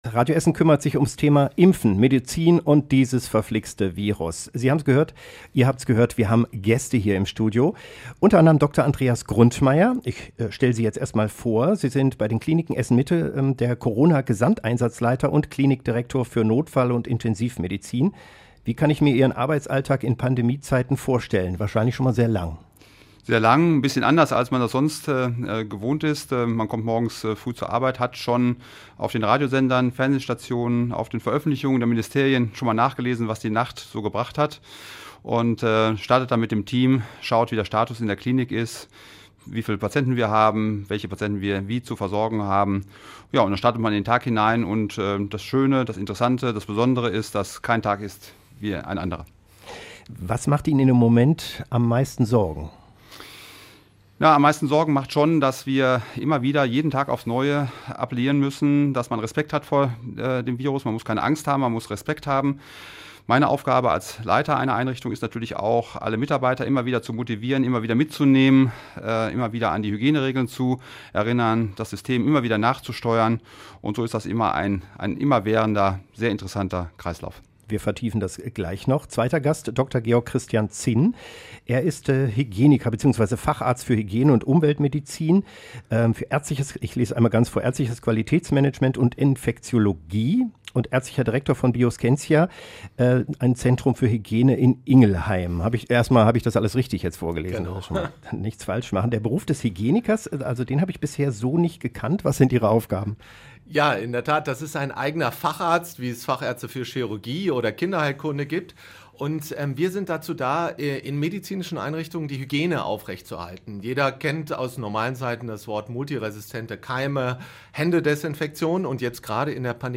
Corona-Talk: Eure Fragen rund um das Thema Impfen
Wir haben mit unseren Experten im Radio Essen-Talk auf das Corona-Jahr 2020 zurück geblickt und Eure Fragen rund um die Corona-Impfungen beantwortet.